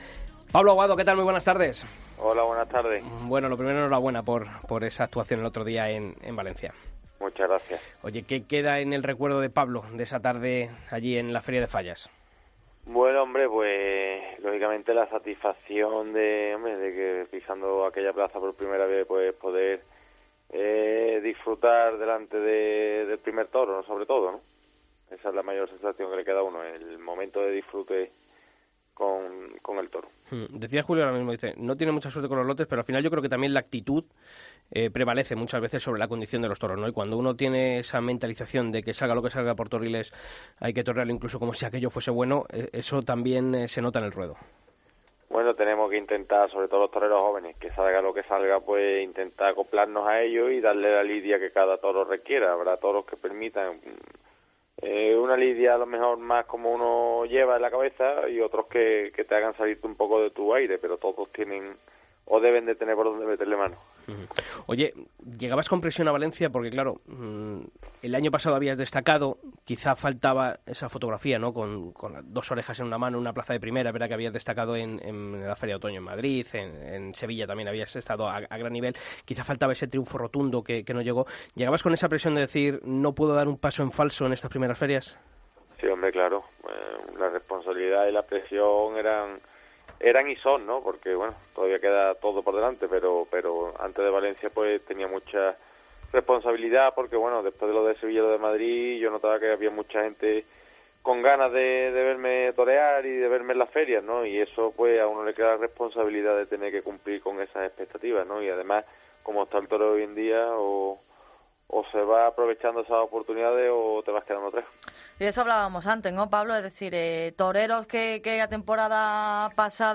Hablamos con el diestro sevillano de su paso por Valencia y de sus próximos compromisos en Madrid y Sevilla.